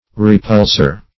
Repulser \Re*puls"er\ (-?r), n.